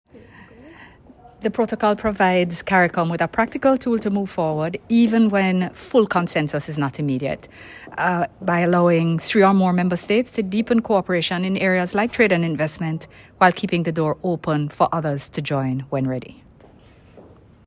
Minister-Johnson-Smith-speaks-on-CARICOM-Protocol.mp3